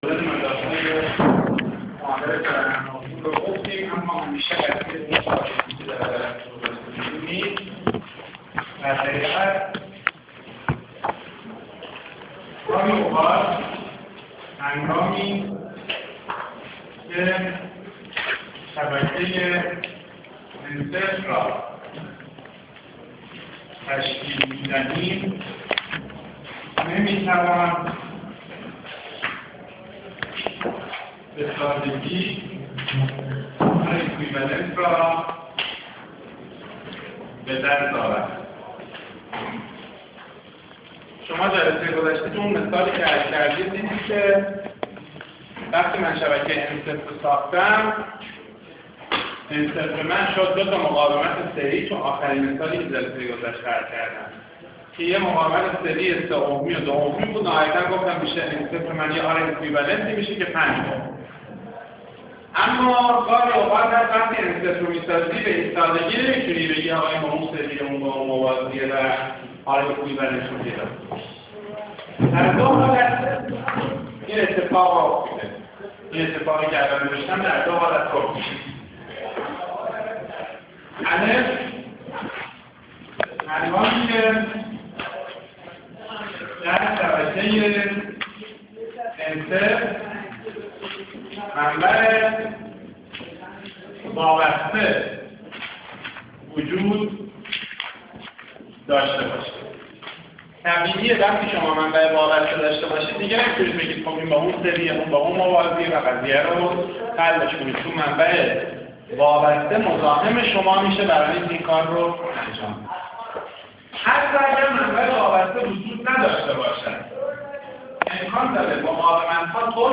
تدریس صوتی درس مدارالکتریکی 1